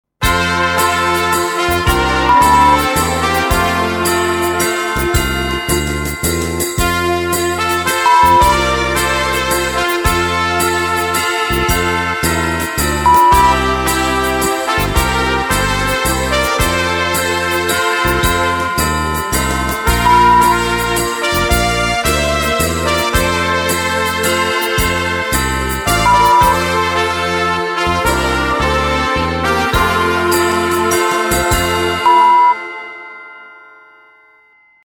Die Hörprobe enthält ein Wasserzeichen (Störtöne).